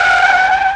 A_BRAKES.mp3